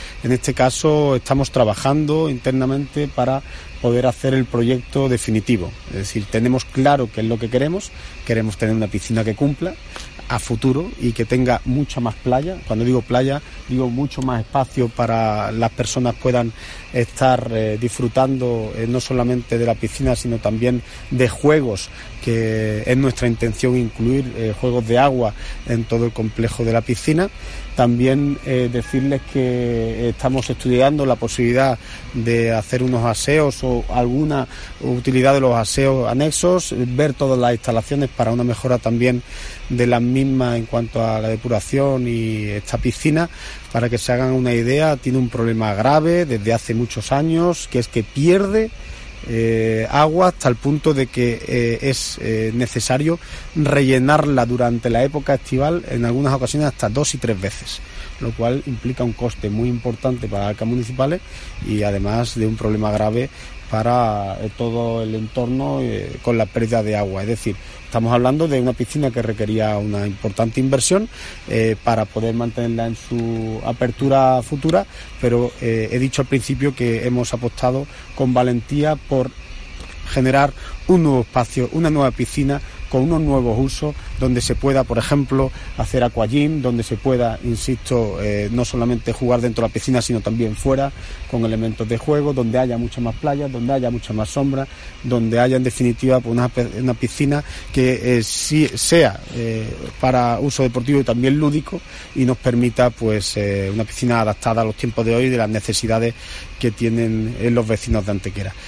El teniente de alcalde delegado de Obras y Mantenimiento, José Ramón Carmona, y la concejal de Deportes, Eugenia Galán, han anunciado en la mañana de hoy martes en rueda de prensa el proyecto de mejora y remodelación integral de la piscina municipal de verano de Antequera, una importante apuesta en materia de infraestructuras deportivas para la ciudad –dicha instalación data del año 1975– que será una realidad de la mano del Plan de Inversiones Financieramente Sostenibles de la Diputación de Málaga.